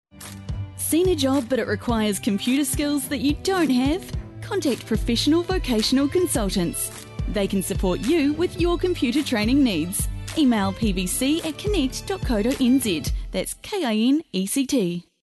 Radio Advert 2